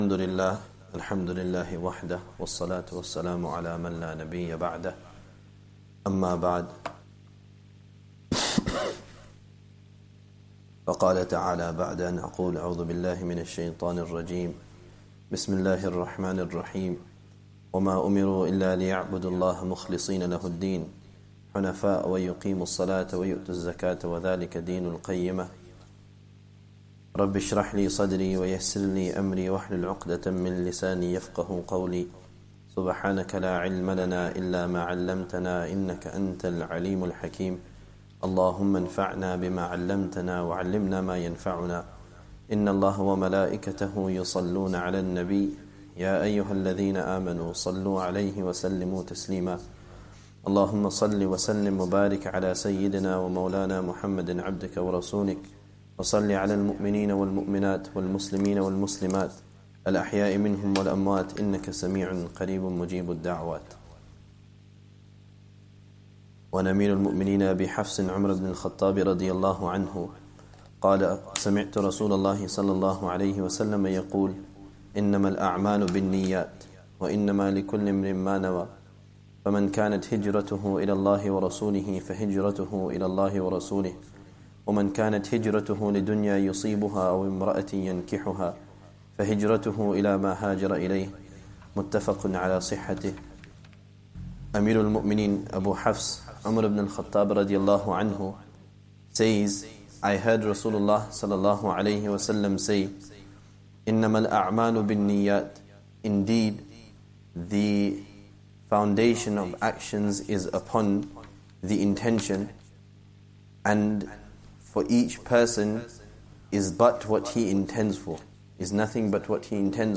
Lessons from Hadeeth